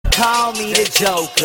rap ringtone Category